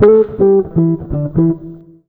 160JAZZ  1.wav